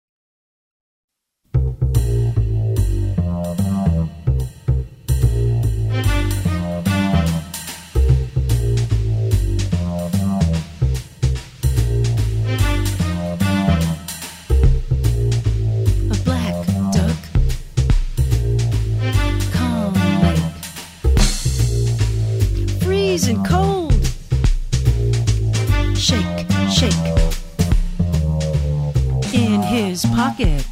▪ The full instrumental track with vocal melody